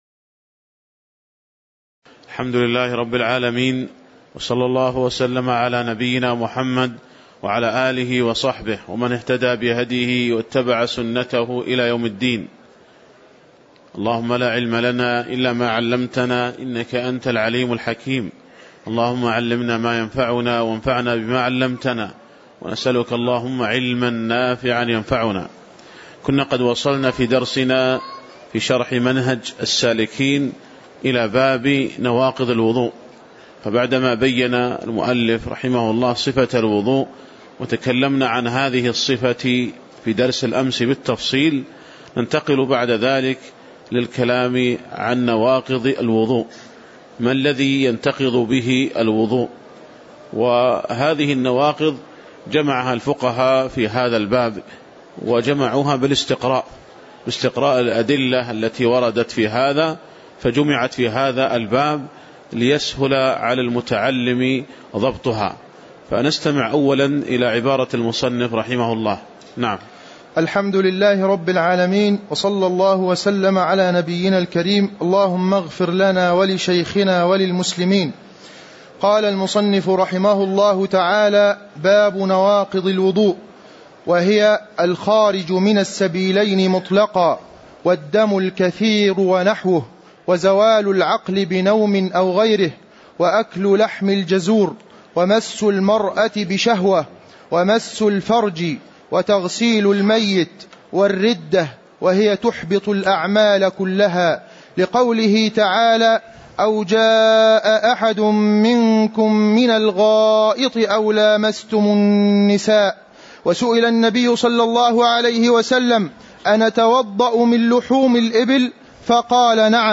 تاريخ النشر ١٤ صفر ١٤٣٨ هـ المكان: المسجد النبوي الشيخ